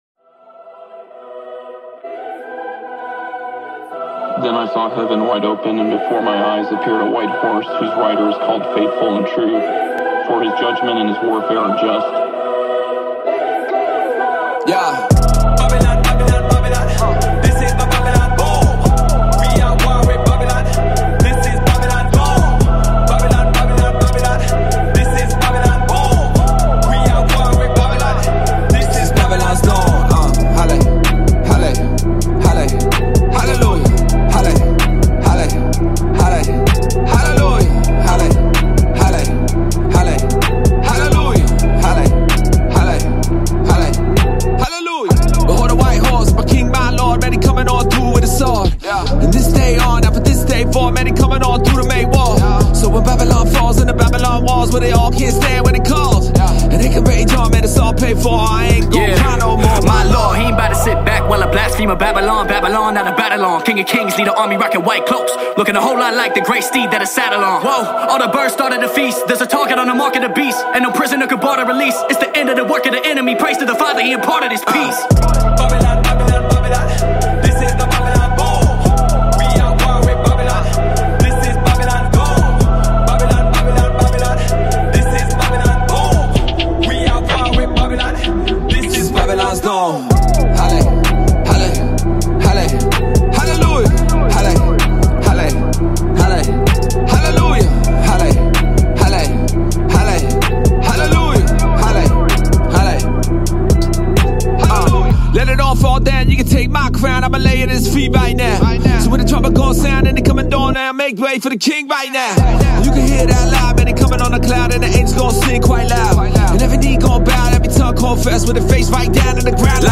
legendary rapper